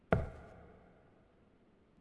FootstepHandlerWood6.wav